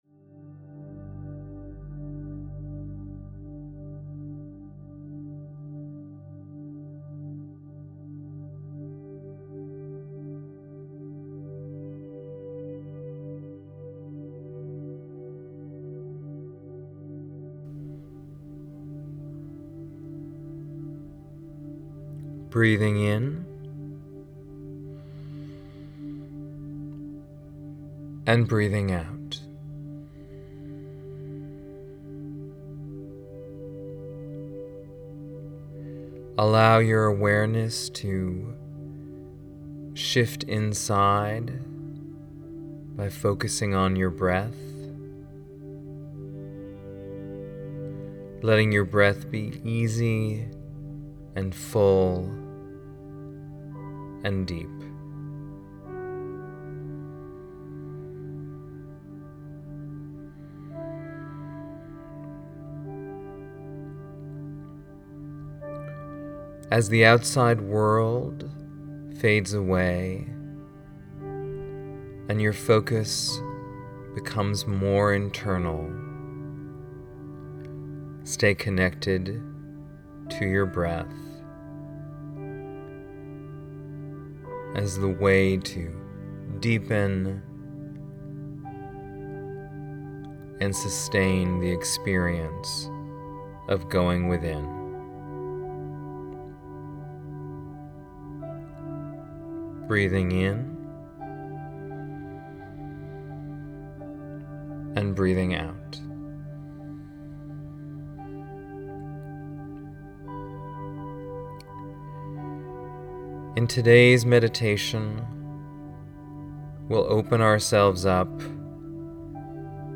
Transformation-Meditation-July-2020-1.mp3